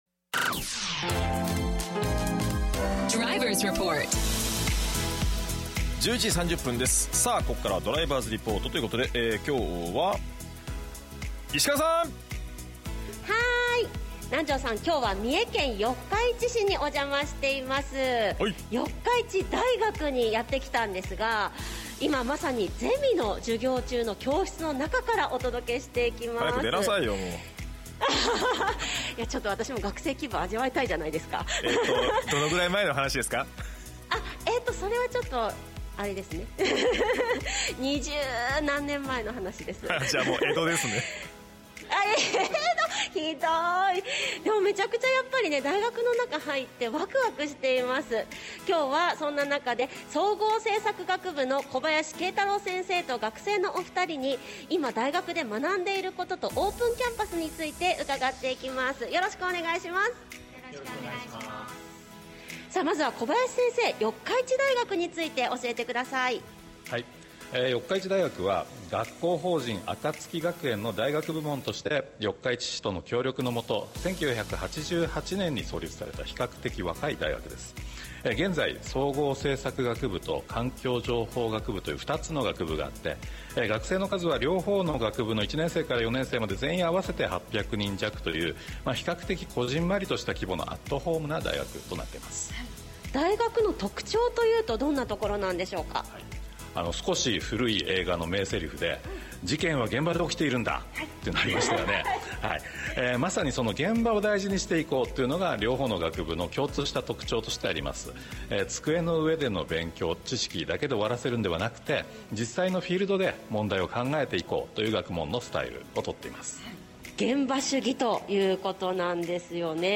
東海ラジオ　DRIVER’S REPORTのコーナーに学生と教員が生出演しました